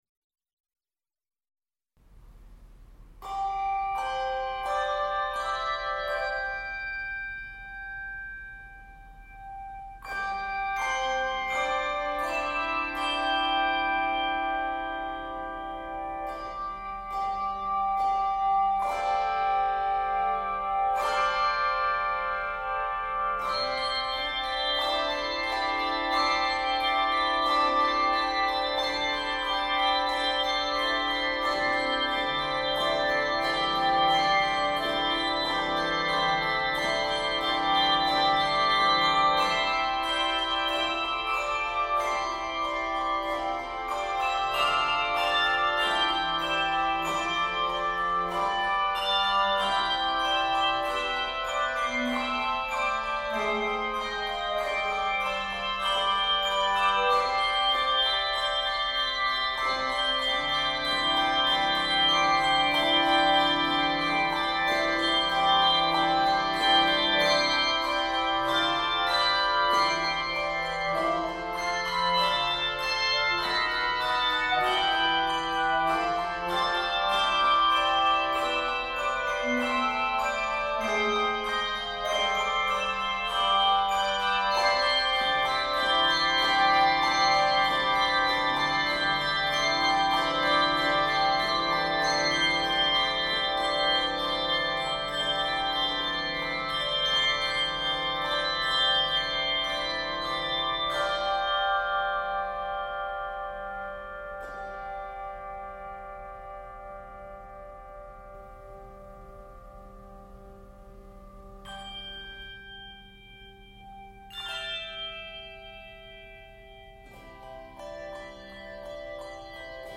Keys of C Major and c minor.